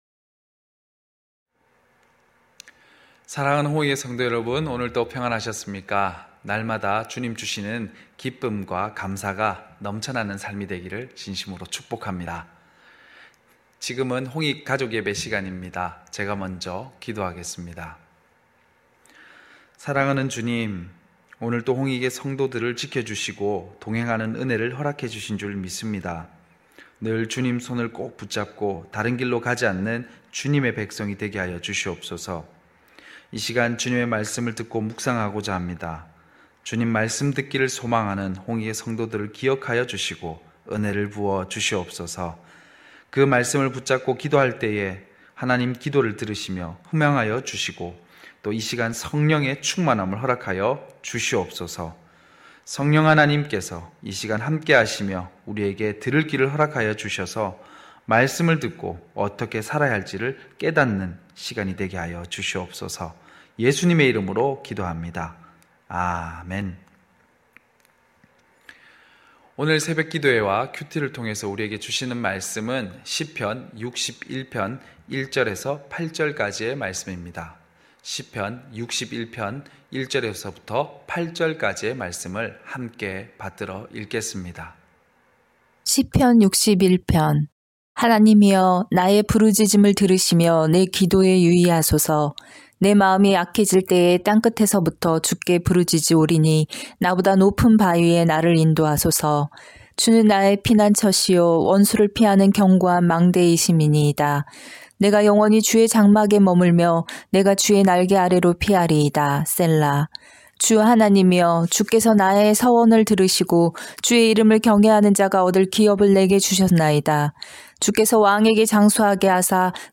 9시홍익가족예배(3월11일).mp3